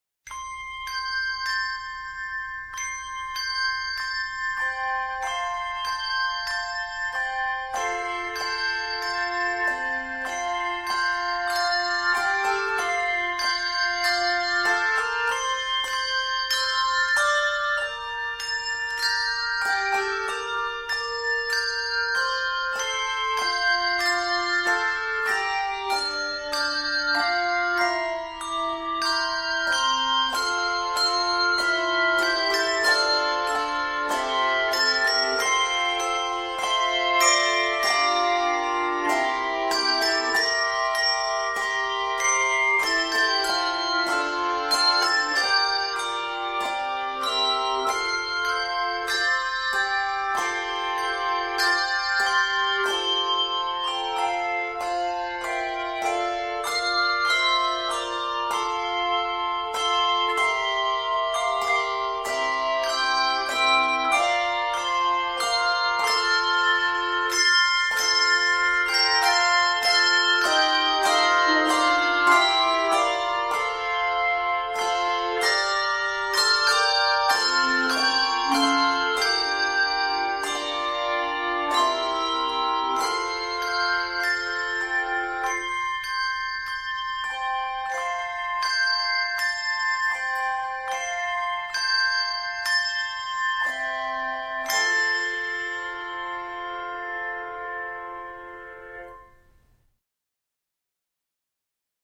This fresh arrangement combines two familiar melodies
this medley is set in F Major.